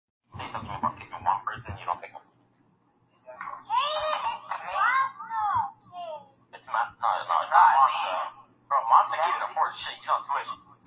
Play, download and share cursing original sound button!!!!
cursing.mp3